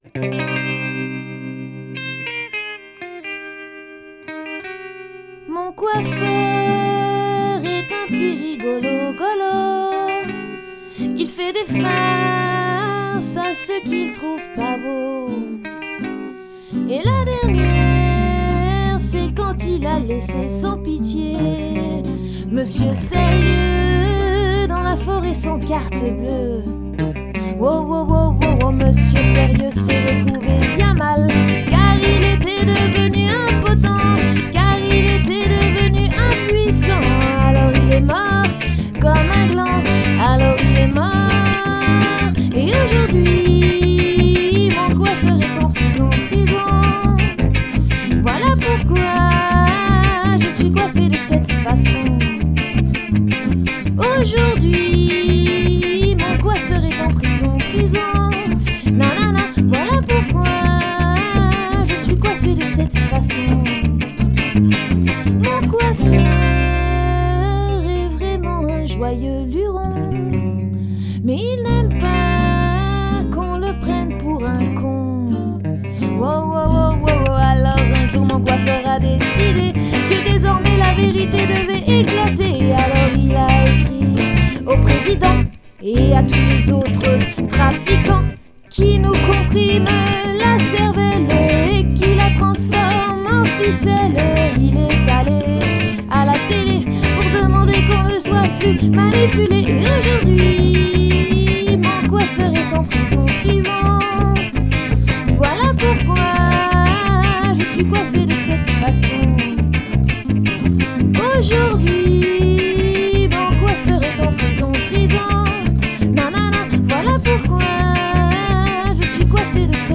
chant, guitare, bruitages
guitares, laud
guitare basse, percussions